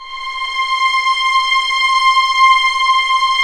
Index of /90_sSampleCDs/Roland L-CD702/VOL-1/STR_Vlns 7 Orch/STR_Vls7 p slo